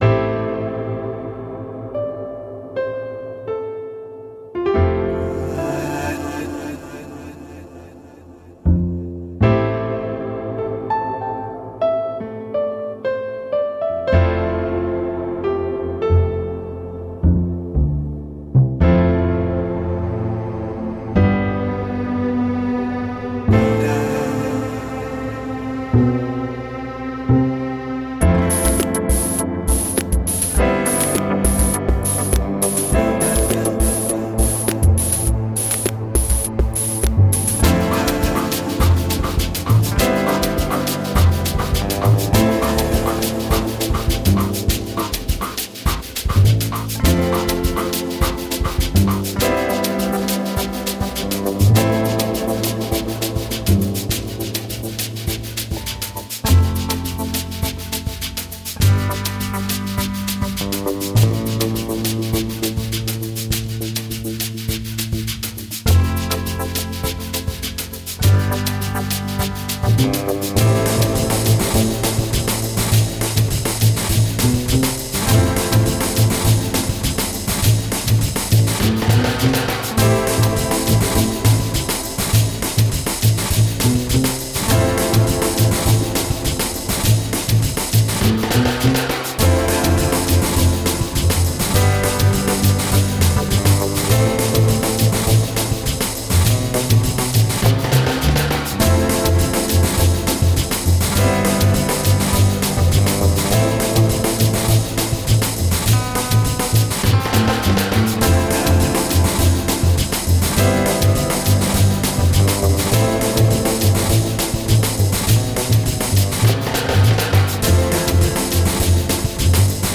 Traversing many of the chillout genres
great downtempo songs.
Tagged as: Electro Rock, Darkwave, Chillout